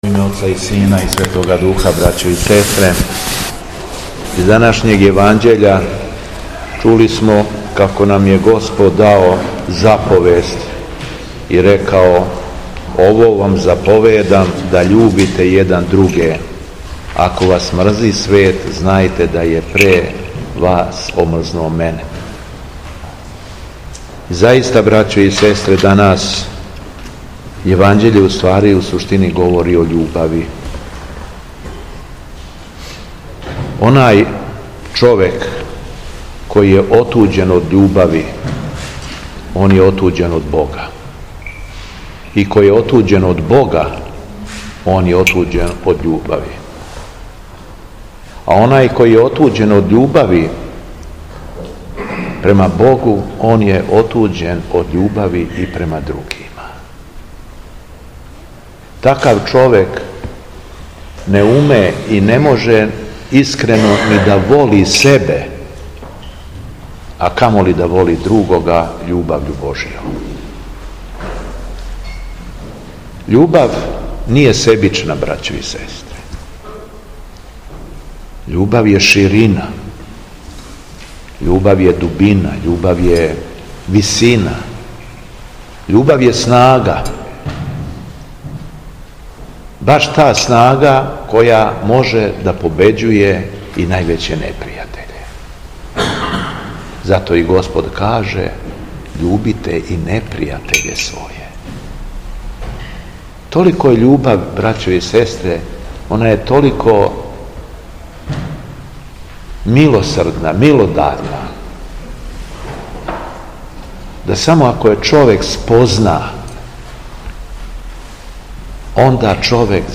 ЛИТУРГИЈСКО САБРАЊЕ У СТАРОЈ ЦРКВИ - Епархија Шумадијска
Беседа Његовог Преосвештенства Епископа шумадијског г. Јована